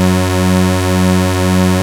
OSCAR 1  F#3.wav